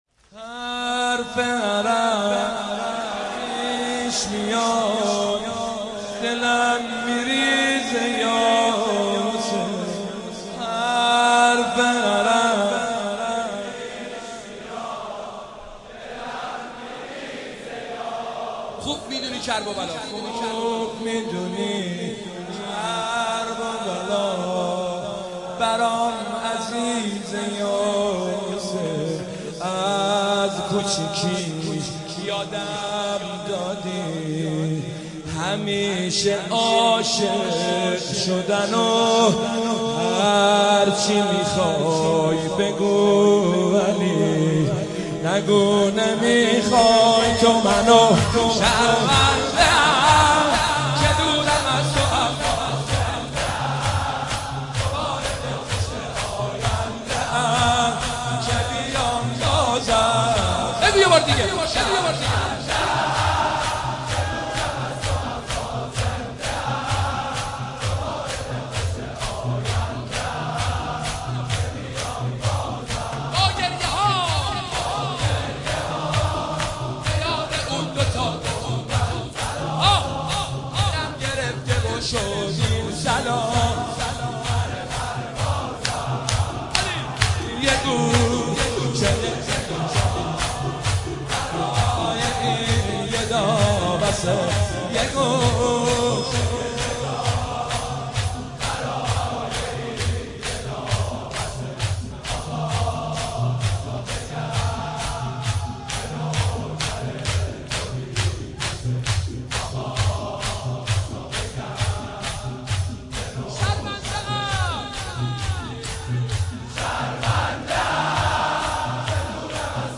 شب نهم تاسوعا محرم 96/07/7
مداحی اربعین